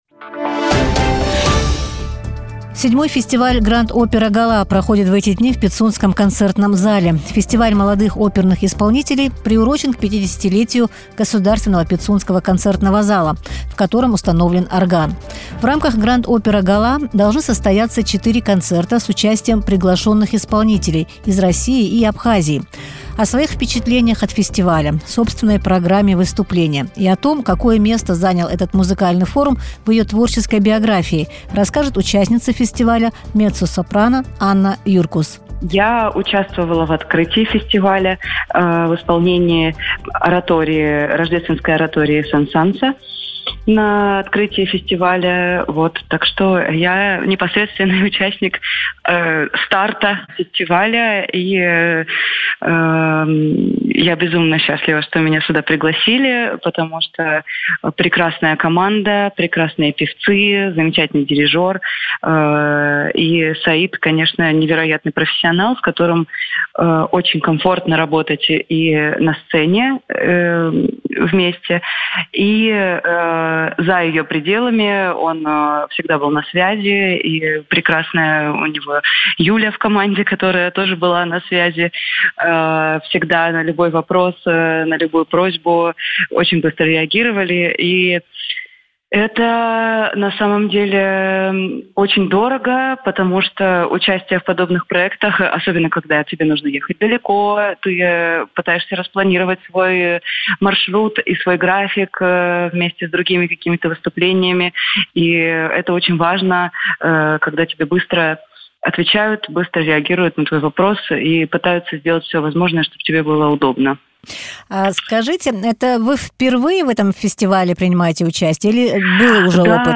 Фестиваль Grand Opera Gala в Пицунде: интервью